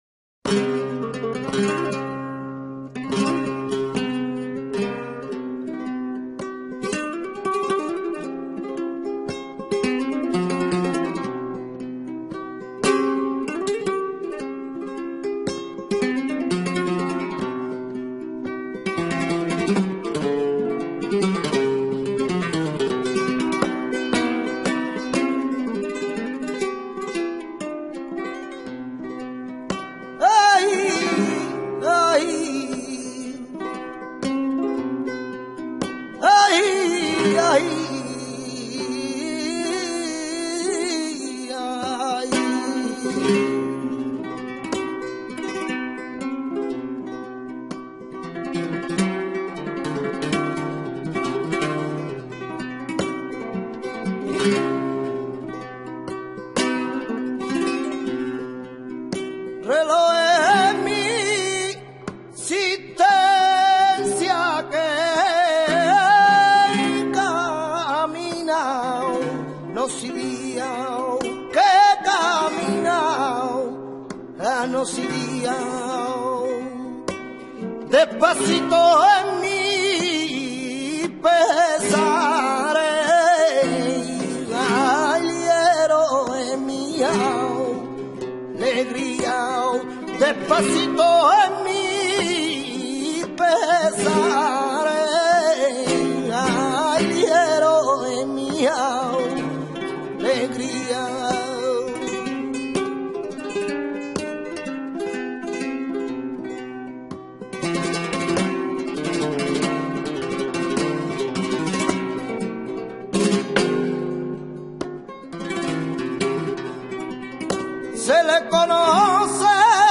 Soleares